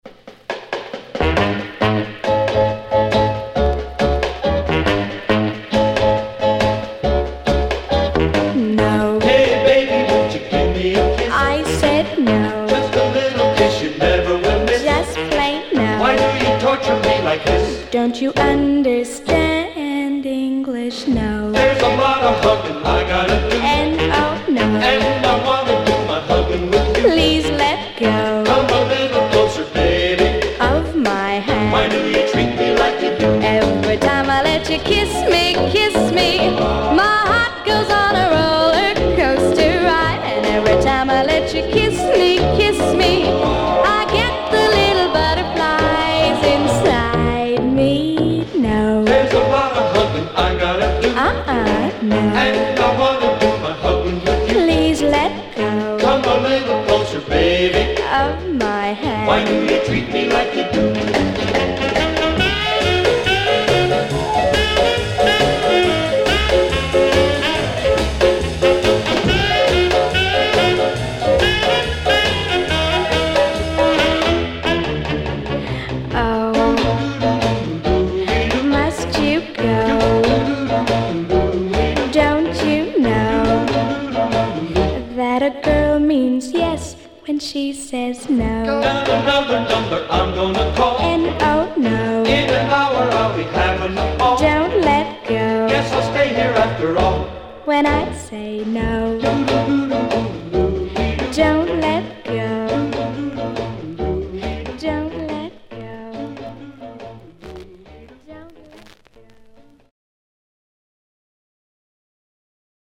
orchestra
some male background singers